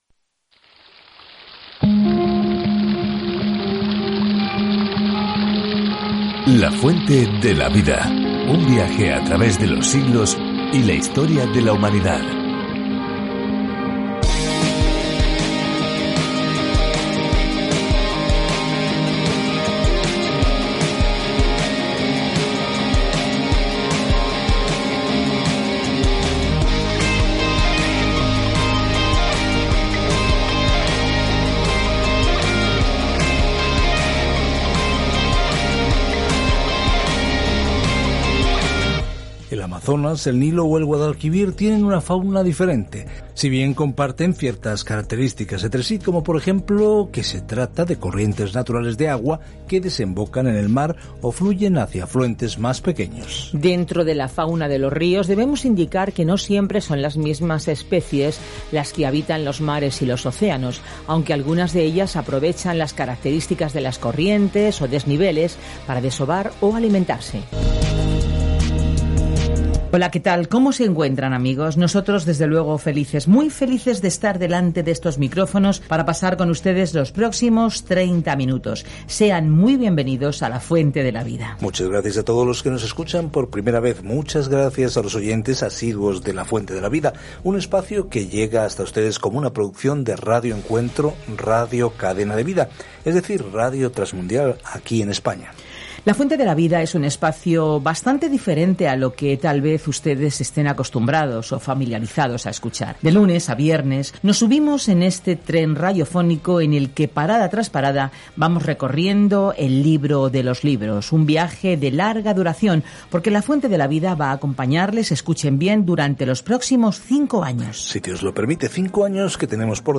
Scripture Habakkuk 1:1-9 Day 1 Start this Plan Day 3 About this Plan Habacuc pregunta con un gran “¿por qué, Dios?” Al comienzo de una serie de preguntas y respuestas con dios sobre cómo trabaja en un mundo malvado. Viaja diariamente a través de Habacuc mientras escuchas el estudio en audio y lees versículos seleccionados de la palabra de Dios.